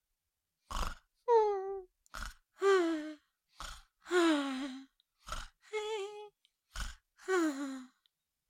Cartoon Little Child, Voice, Snore, Snoring 2 Sound Effect Download | Gfx Sounds
Cartoon-little-child-voice-snore-snoring-2.mp3